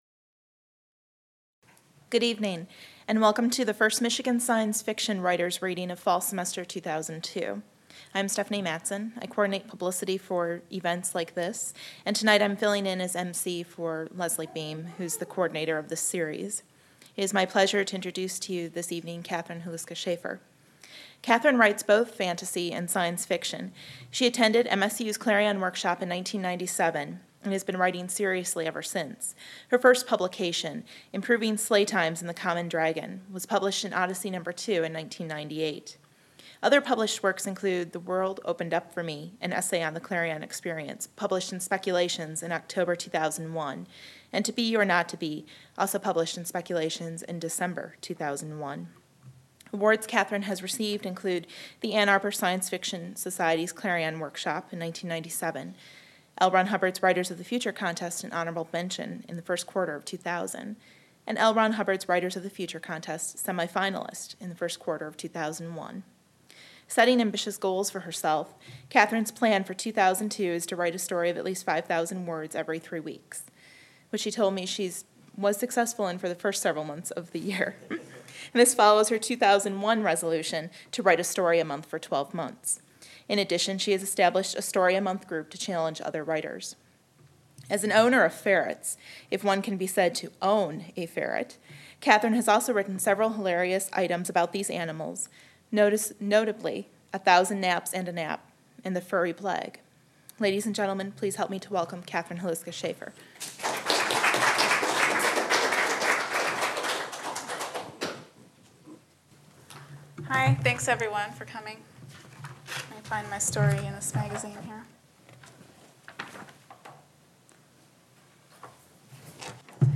Part of the MSU Libraries' Michigan Writers Series. Held at the Main Library.